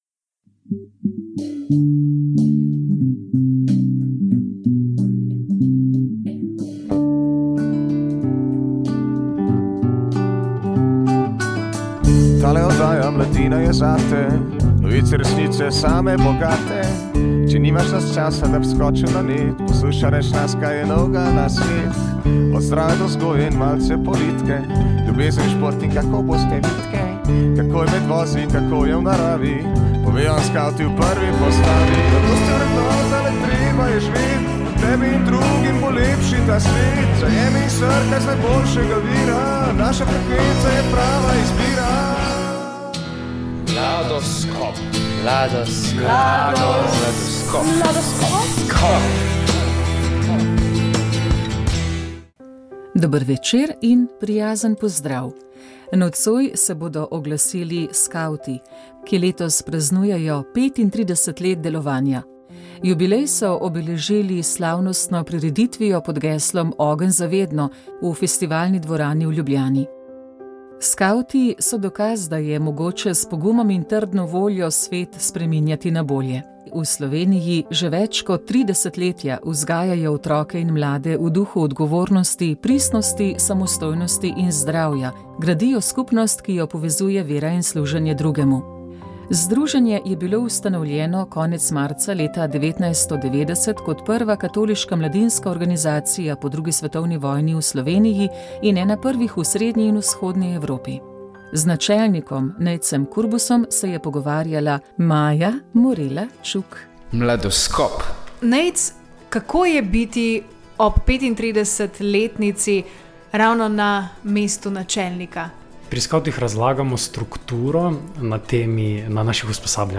sodobna krščanska glasba